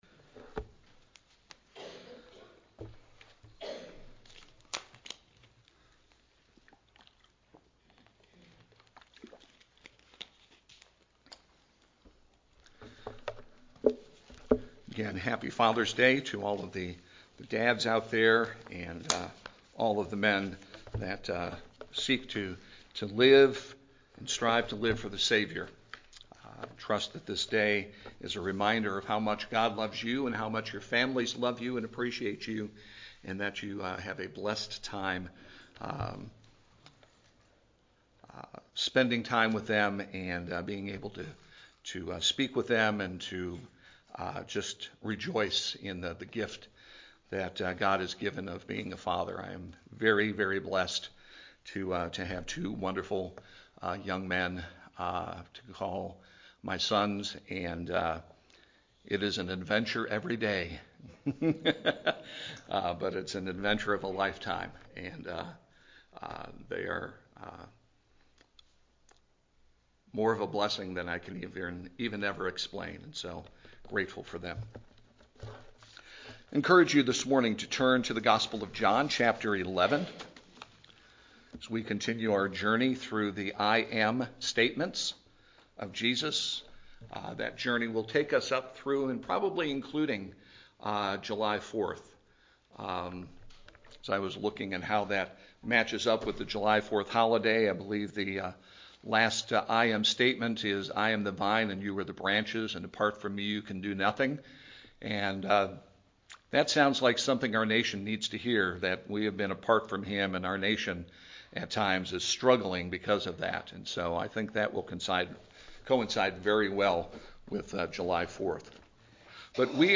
Music – The Father’s House; Sermon – Jesus, the Resurrection and the Life